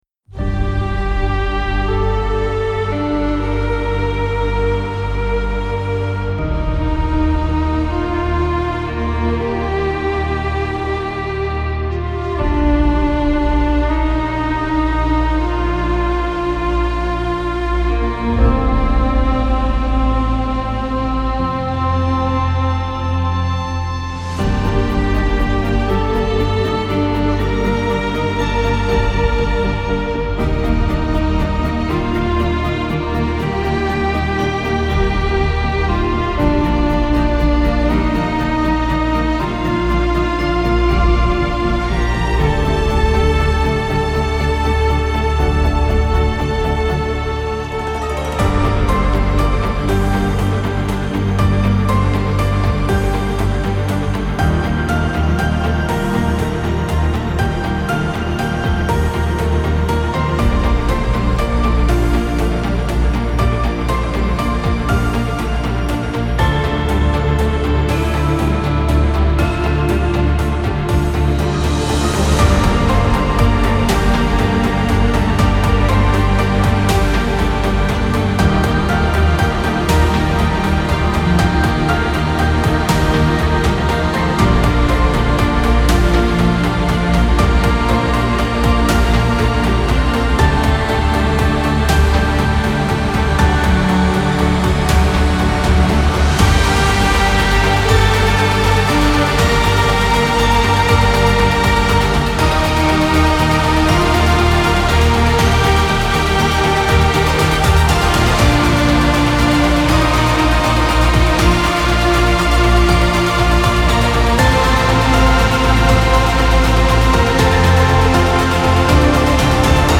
اپیک , الهام‌بخش , حماسی , موسیقی بی کلام
موسیقی بی کلام الهام بخش موسیقی بی کلام حماسی